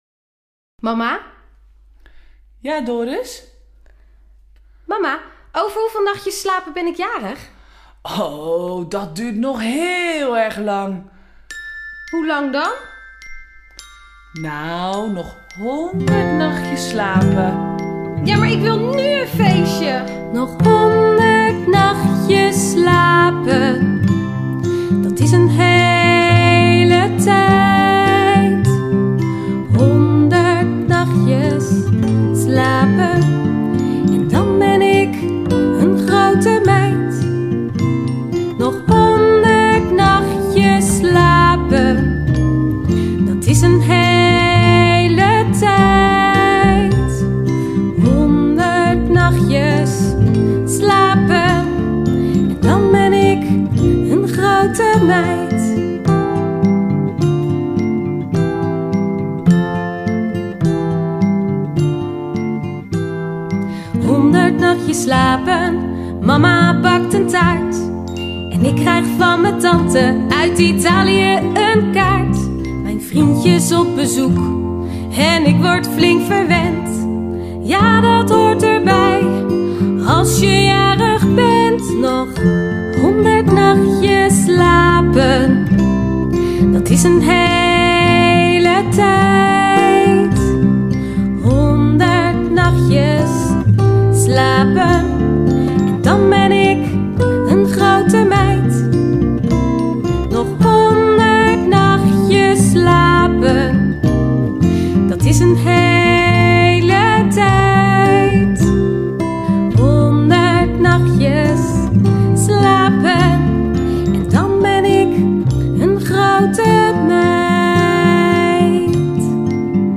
Actividad-3.1-Liedje-Nog-100-nachtjes-slapen.mp3